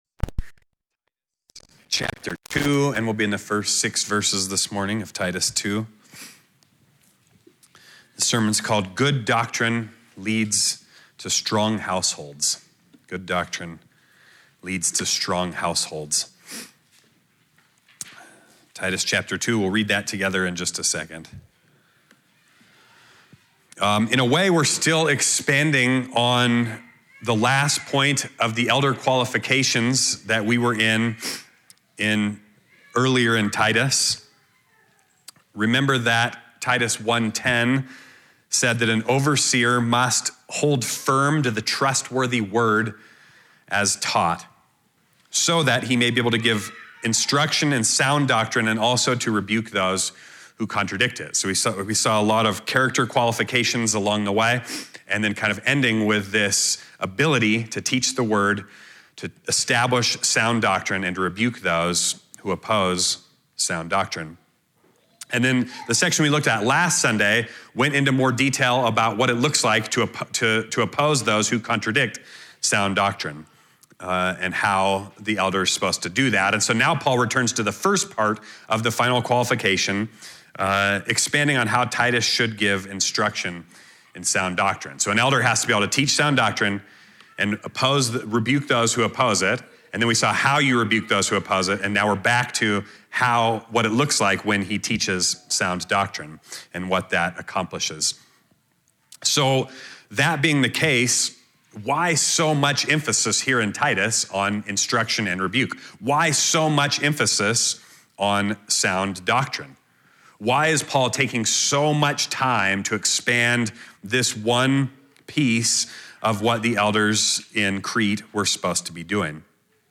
Location: Gospel Church Durango